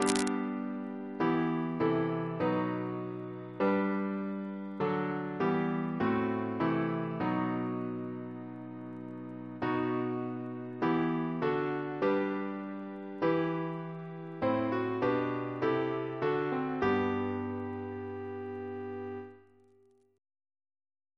Double chant in G Composer: Stephen Elvey (1805-1860), Organist of New College, Oxford; George's brother Reference psalters: ACB: 28; ACP: 9; PP/SNCB: 116